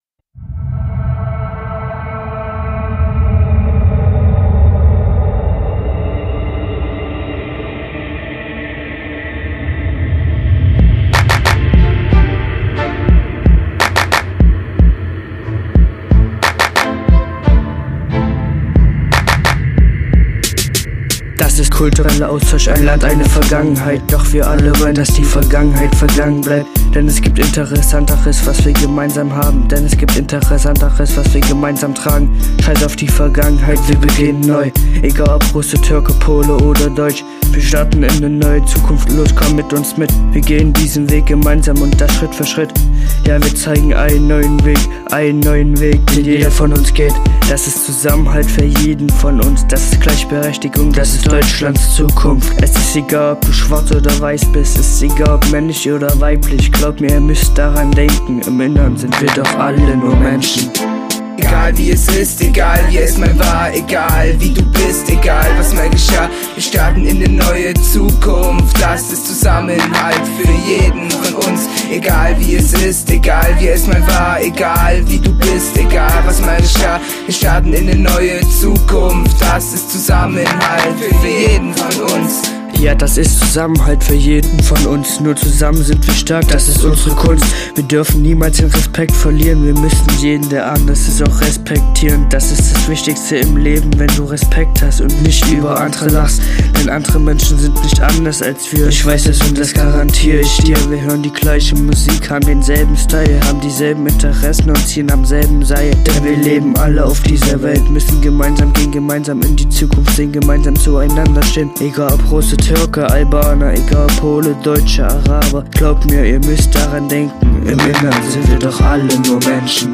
"Nowa przyszłość" - rap przygotowany przez drużynę ASB z Lipska | TriM